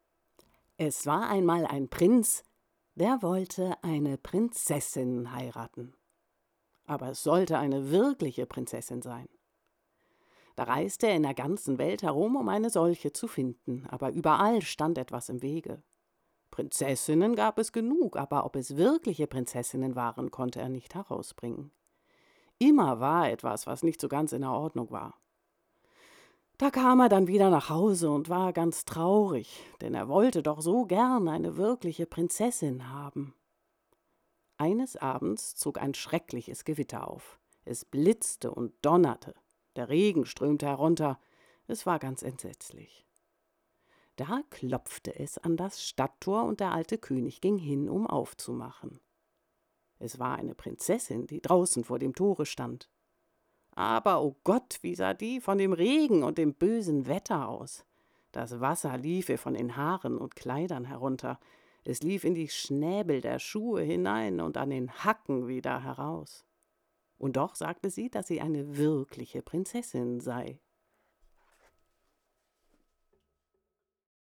Sprechbeispiele
Märchen